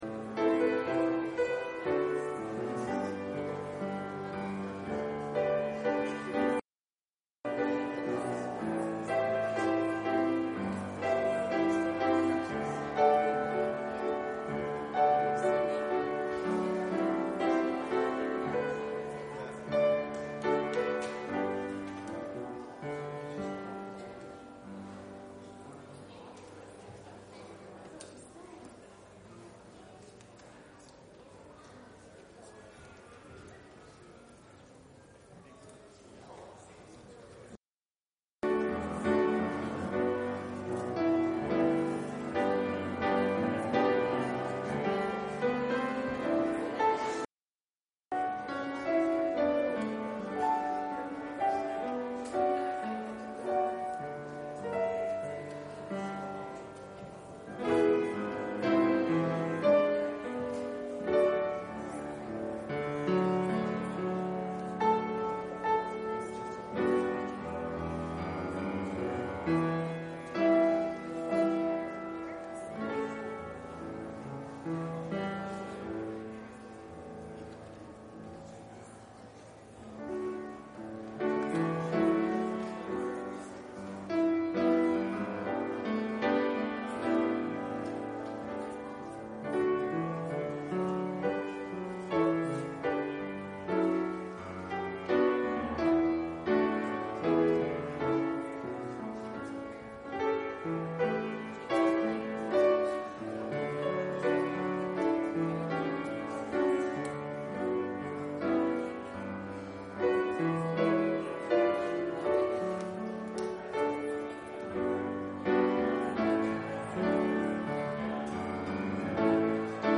Public Reading of Holy Scripture
Service Type: Sunday Afternoon